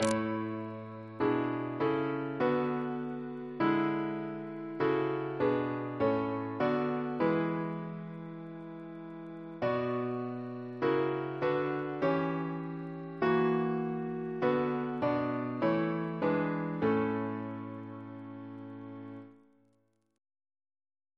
Double chant in A Composer: Charles L. Naylor (1869-1945) Reference psalters: RSCM: 101